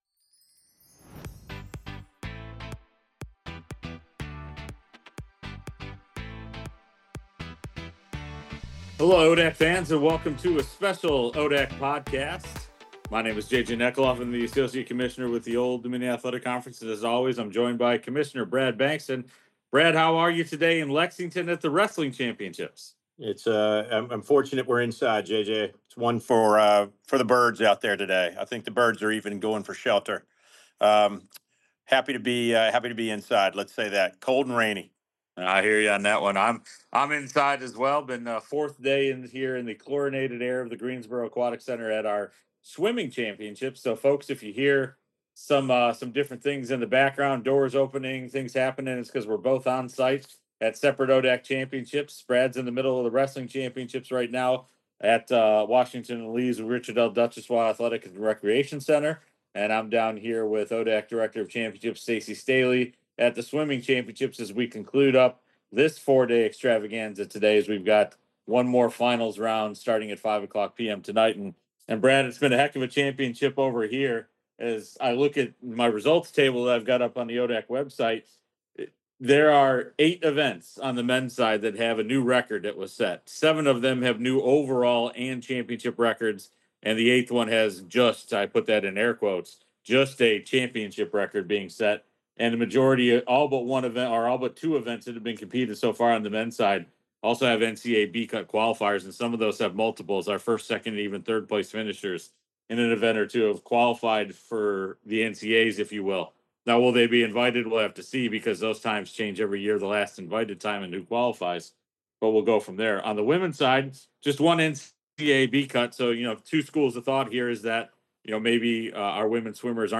The ODAC Podcast: Episode 9 - On-Site at the Swimming and Wrestling Championships